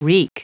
Prononciation du mot wreak en anglais (fichier audio)
Prononciation du mot : wreak